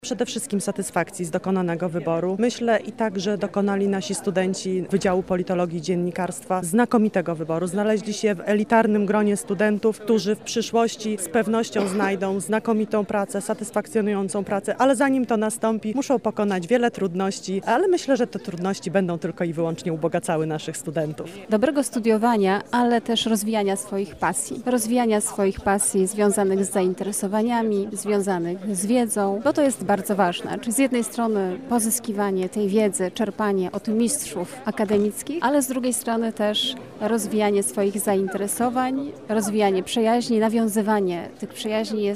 Podczas Immatrykulacji ślubowanie złożyło około 850 studentów wszystkich 9 kierunków wydziału.
życzenia